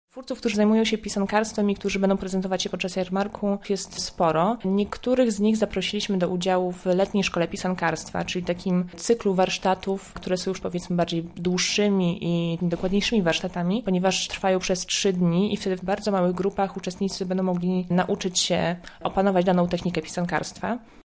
kulturoznawca.